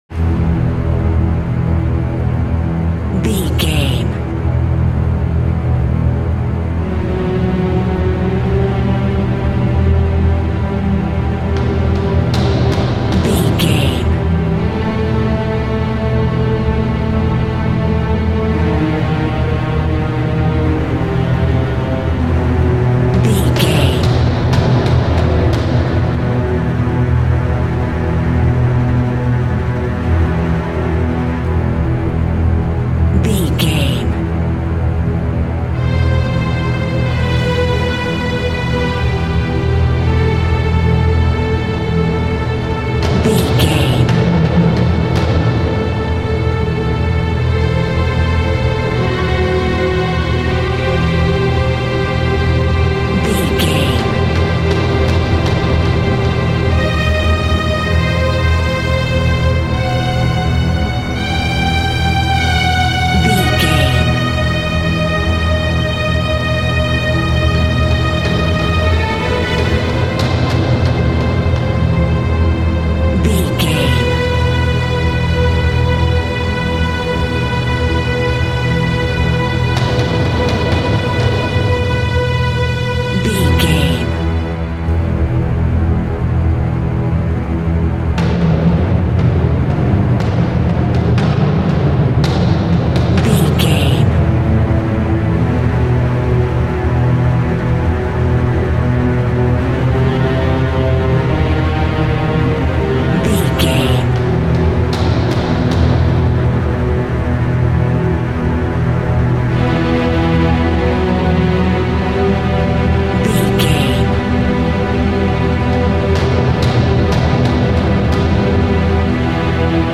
Scary Soundtrack of Strings.
In-crescendo
Thriller
Aeolian/Minor
ominous
eerie
violin
cello
double bass
brass
percussion
orchestra
viola